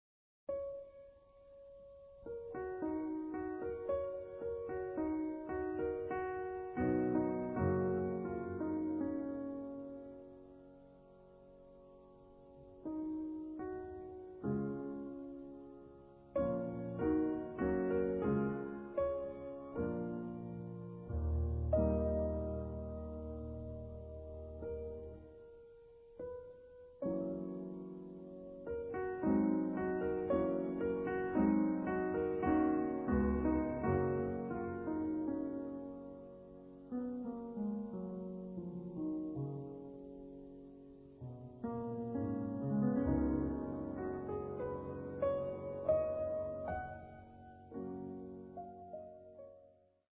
Grand Piano